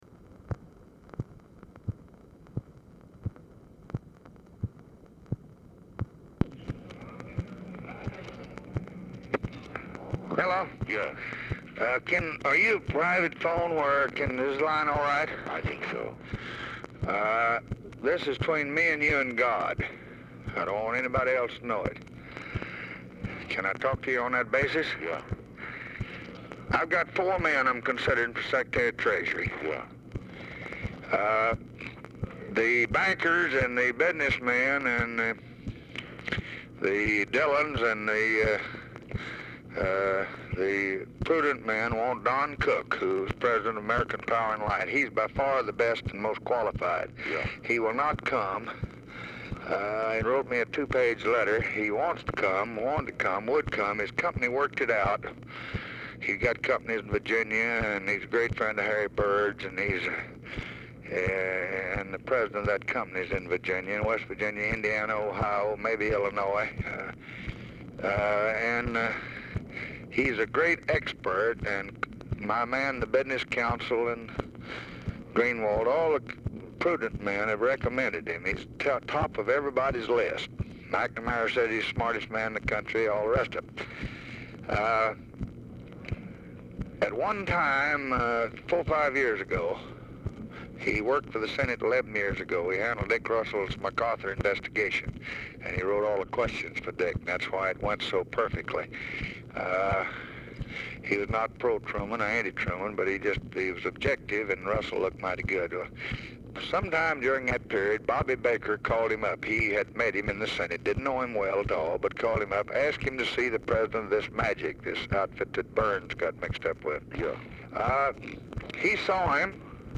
Telephone conversation # 7070, sound recording, LBJ and EVERETT DIRKSEN, 3/16/1965, 12:55PM | Discover LBJ
Format Dictation belt
Location Of Speaker 1 Oval Office or unknown location
Specific Item Type Telephone conversation